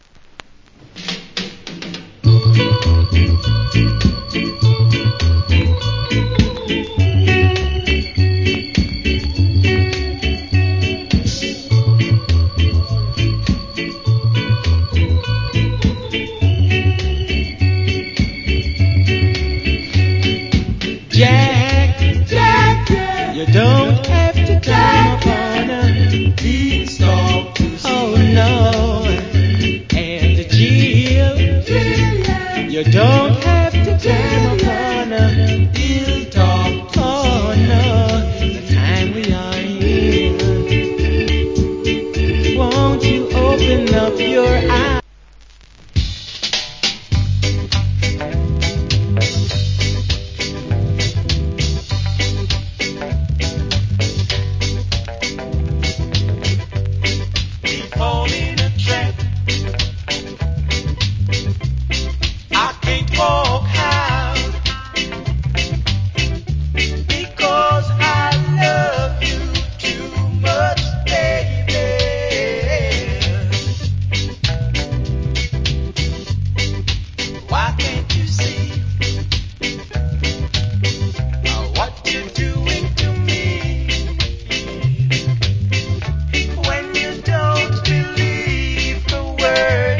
Cool Reggae Vocal.